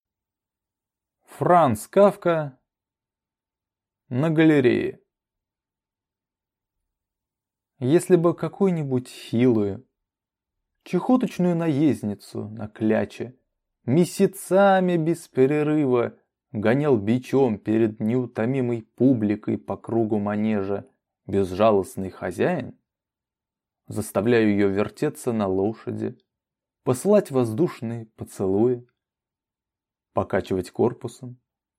Aудиокнига На галерее